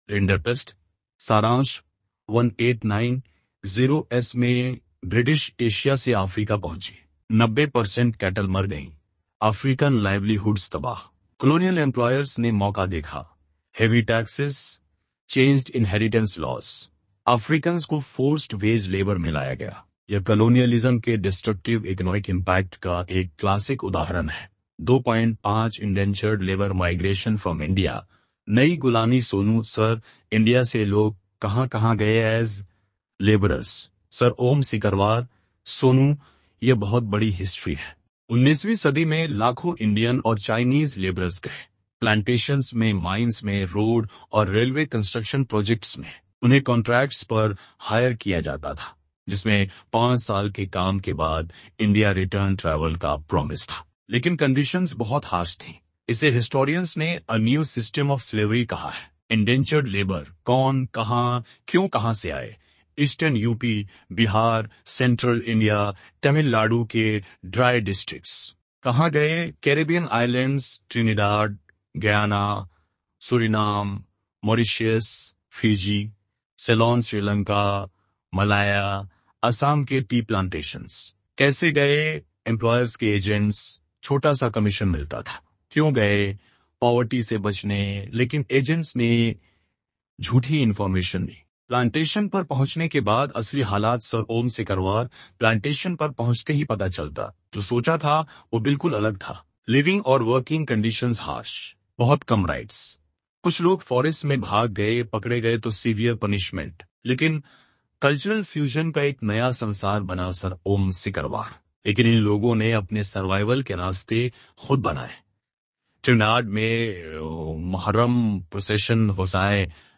kabir_tts_audio-30-1.mp3